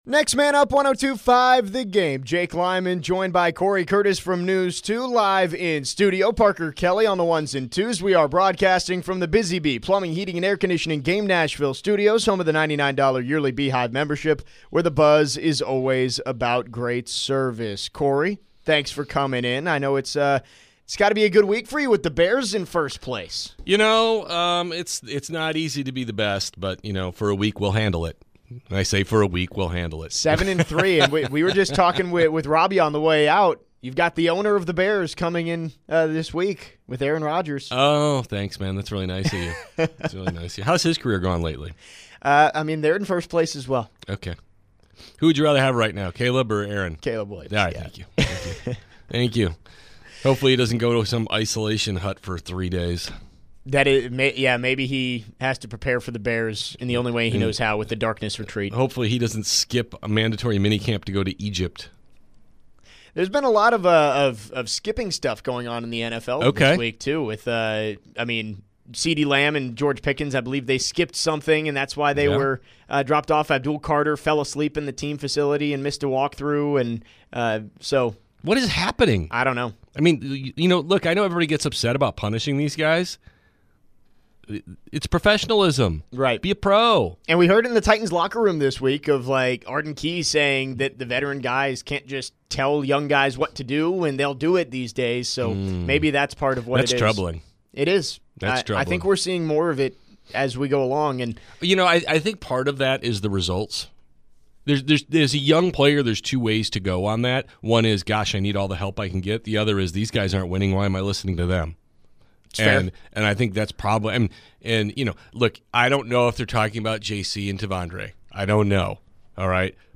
in studio today